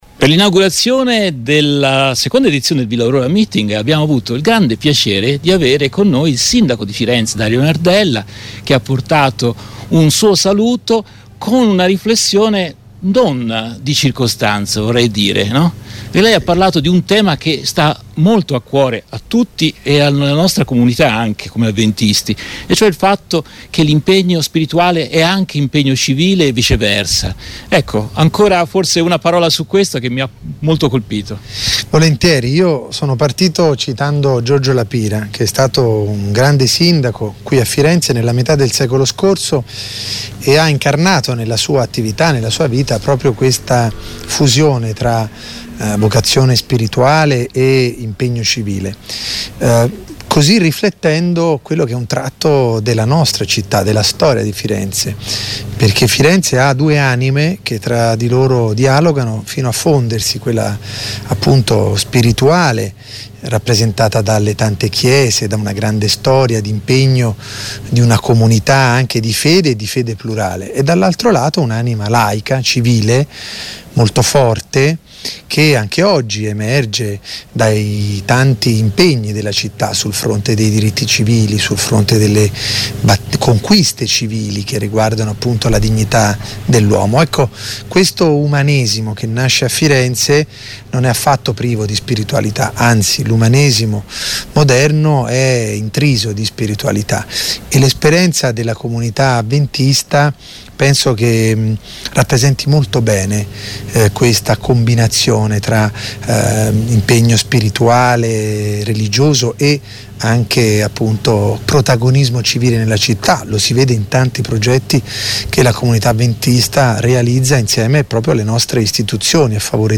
Voci dal Villa Aurora Meeting 2023: il sindaco Dario Nardella